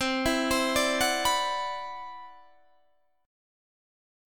CM#11 chord